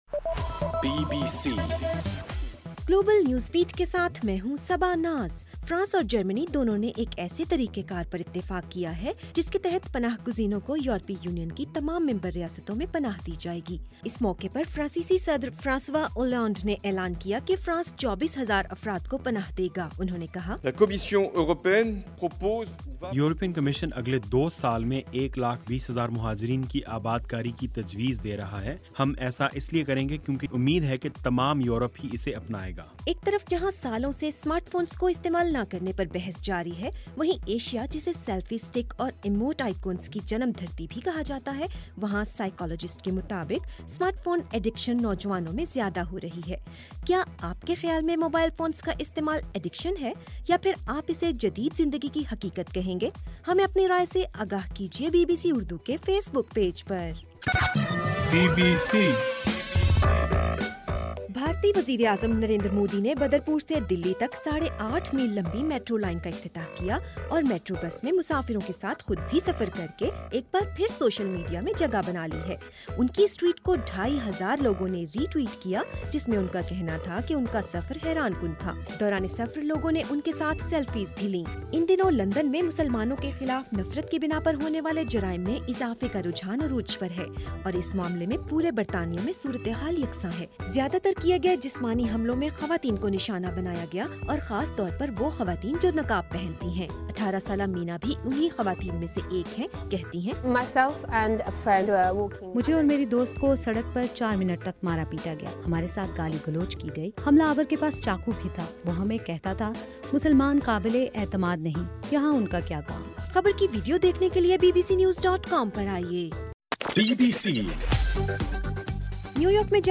ستمبر7: رات 8 بجے کا گلوبل نیوز بیٹ بُلیٹن